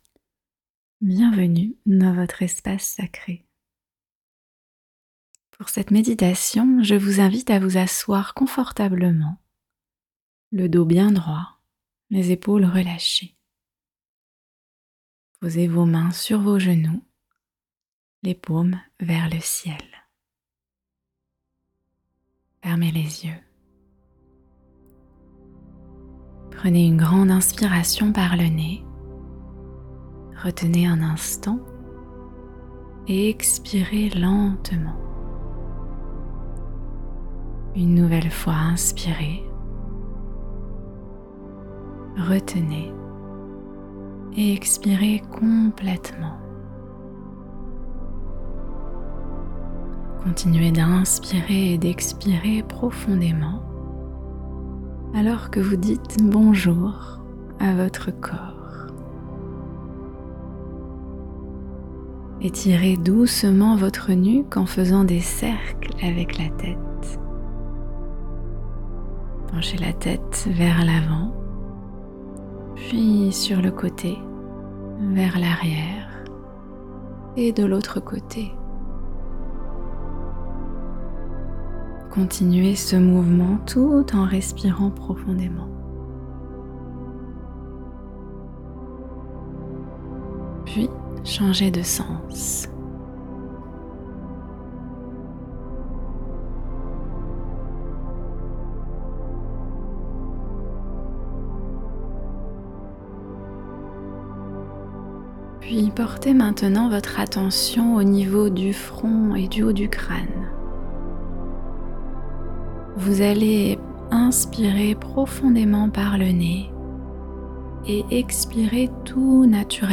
Lors de cette méditation guidée, tu seras invité.e à :